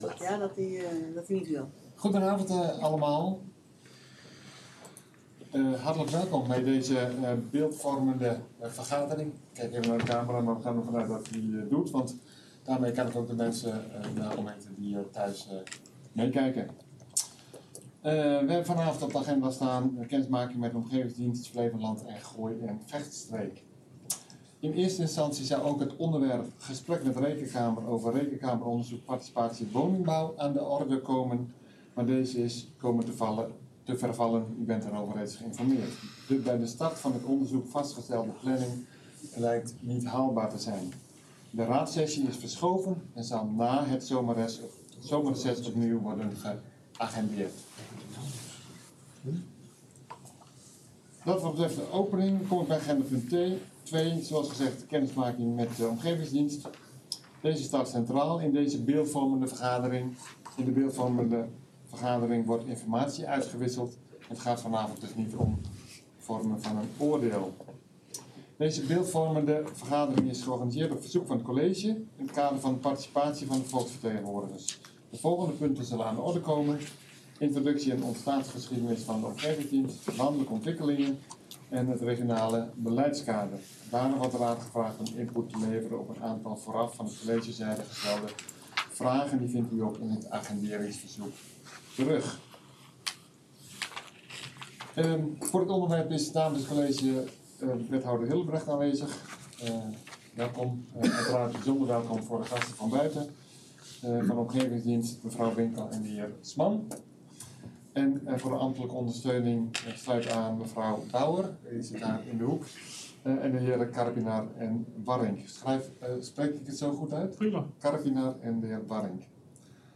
Beeldvormende vergadering 16 mei 2023 19:30:00, Gemeente Dronten
Locatie: Raadzaal